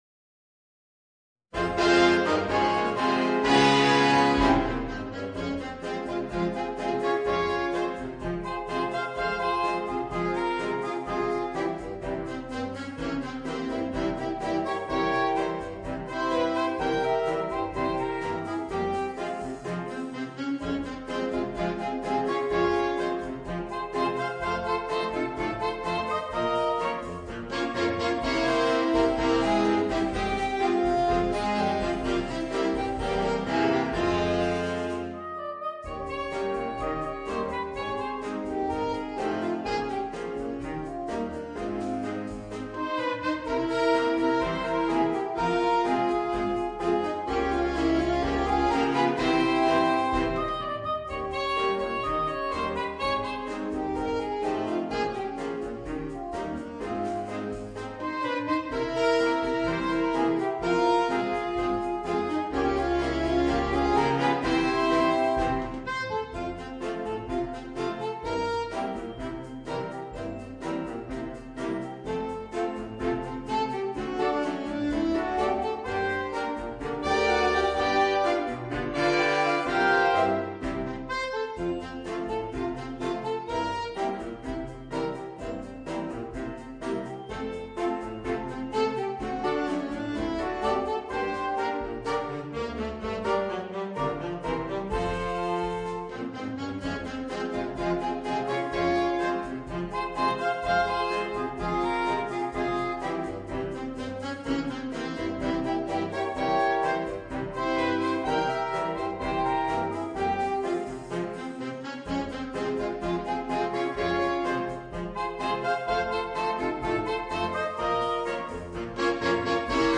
für acht Saxophone
Instrumentalnoten für Saxophon PDF